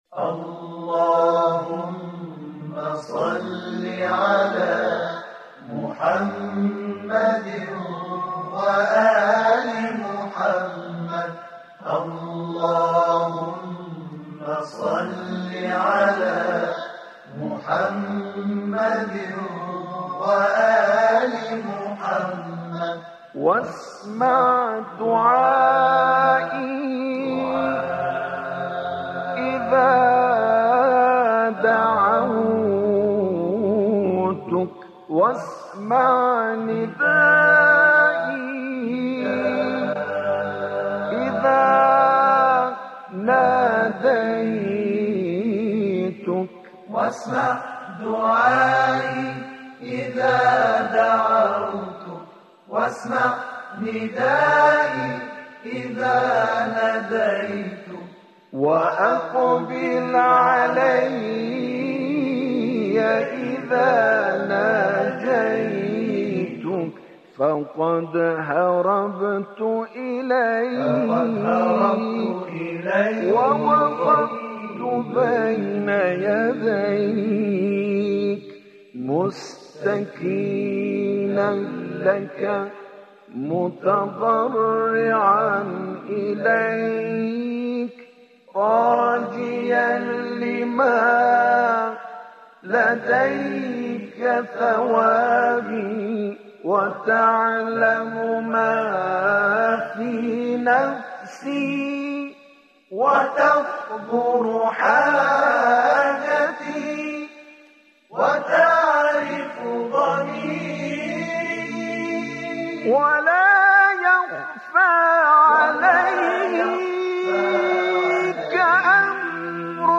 به مناسبت ماه شعبان، مناجات شعبانیه را با نوای گروه تواشیح اهل بیت‌(ع) می‌شنوید.